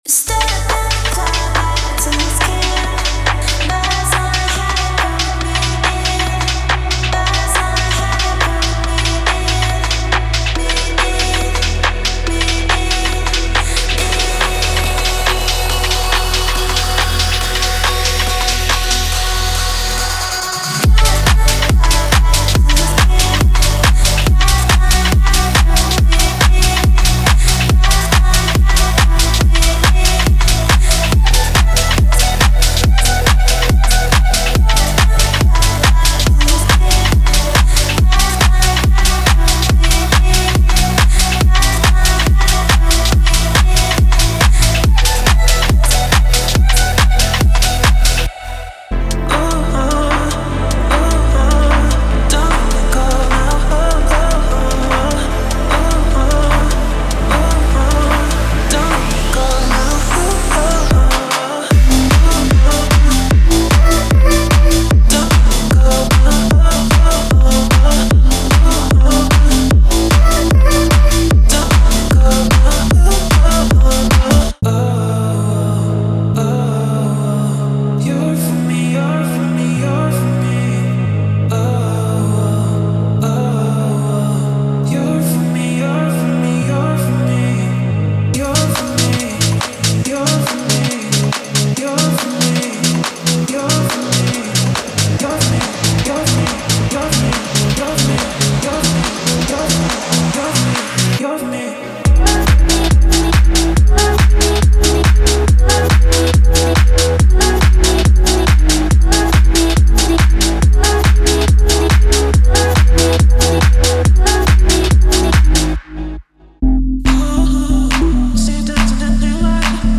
House Vocals